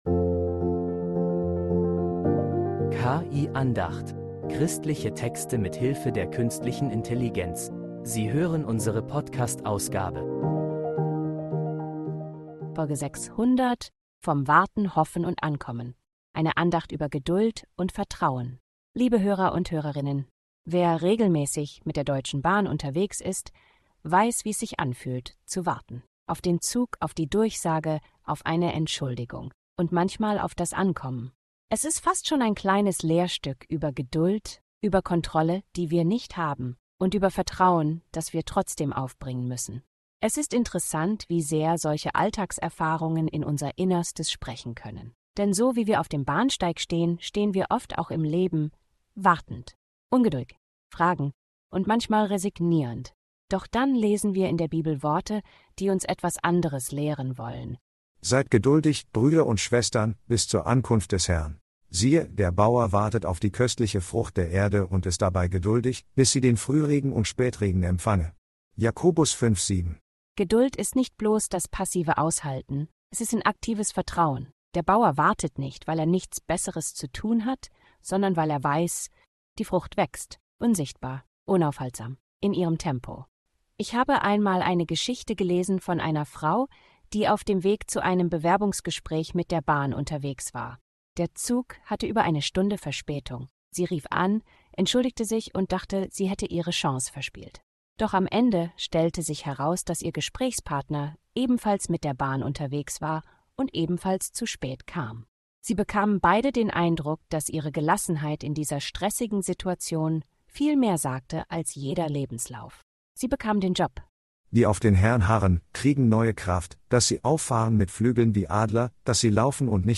Eine Andacht über Geduld und Vertrauen